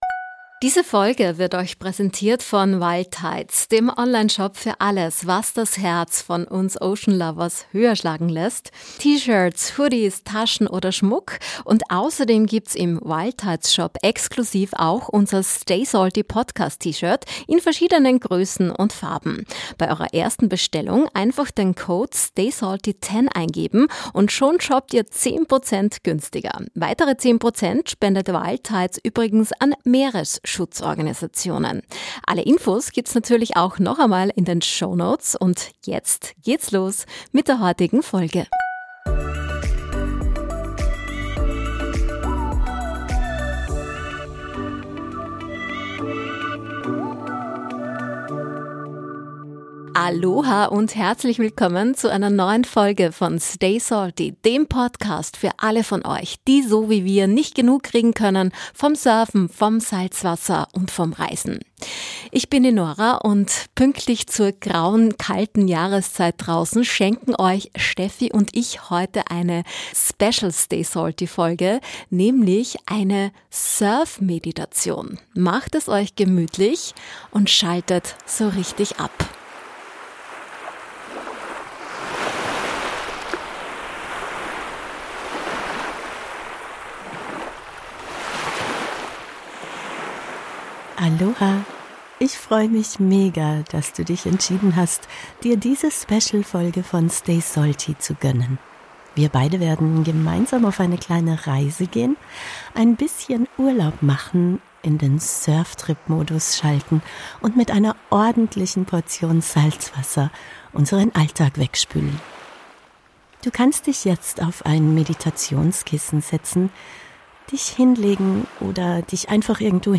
Du möchtest dir trotzdem hier und jetzt eine Auszeit vom – vielleicht gerade zu stressigen und fordernden – Alltag nehmen? Dann ist diese Surf-Meditation eventuell genau das Richtige für dich!